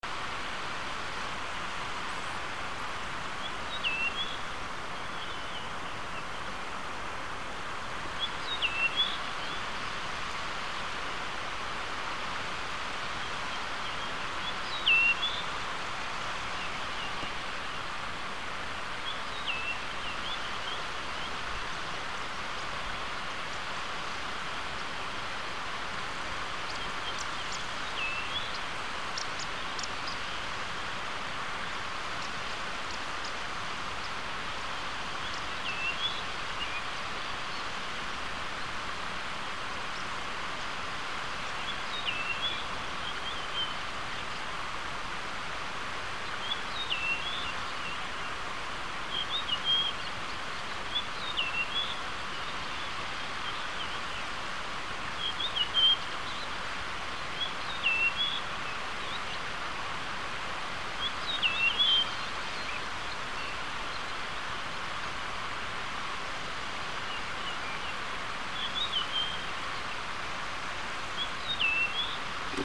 Lepsämä punakylkirastas